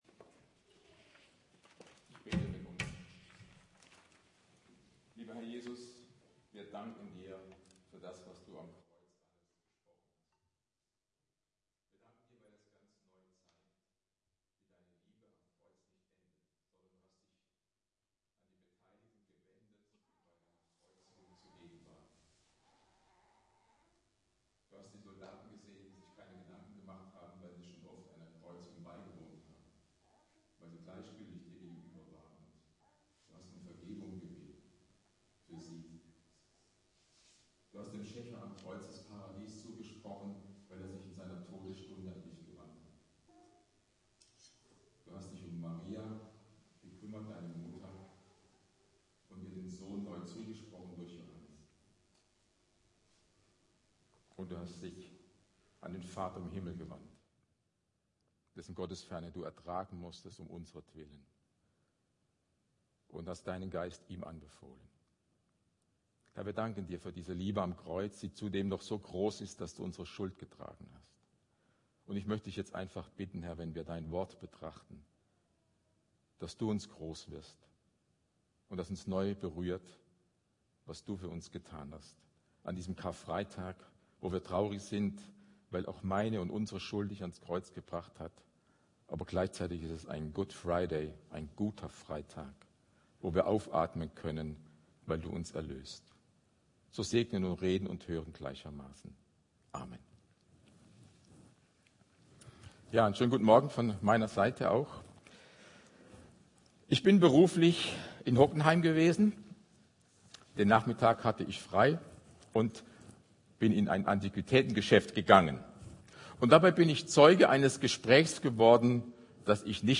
Predigt Briefe